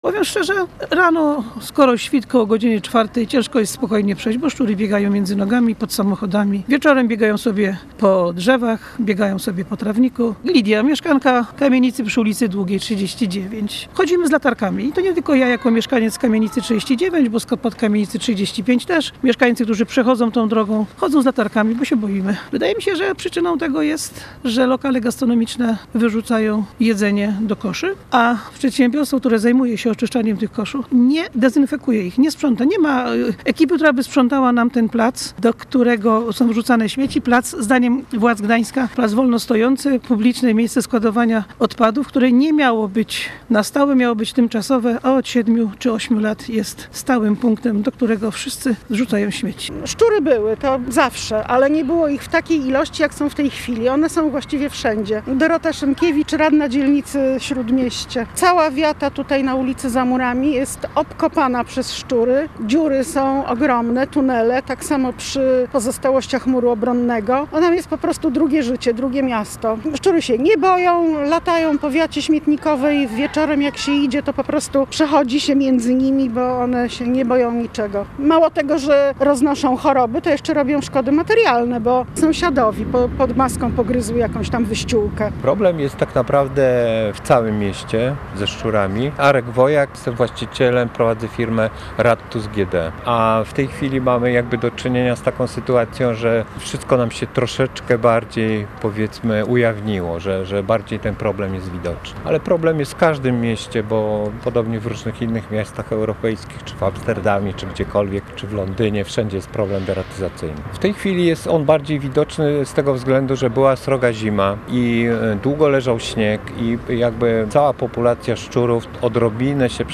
Wieczorem są na drzewach i na trawniku – wskazuje jedna z mieszkanek.